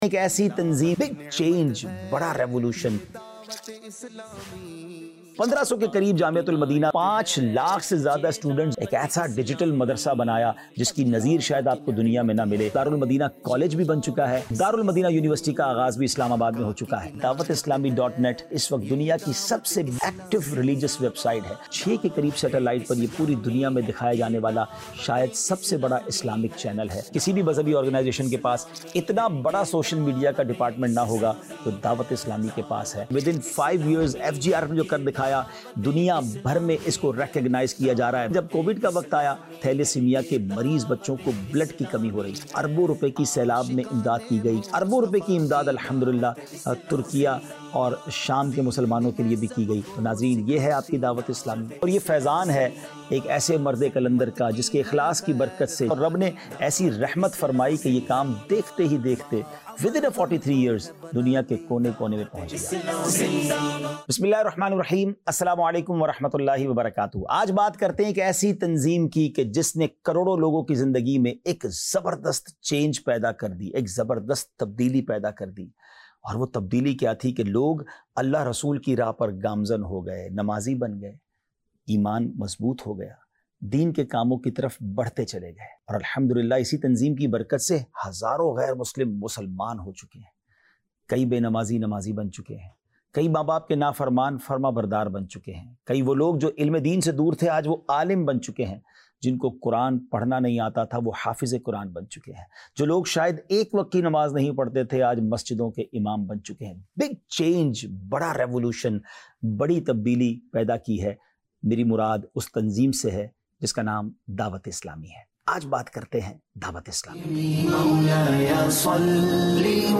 Introduction To Dawateislami | Documentary 2025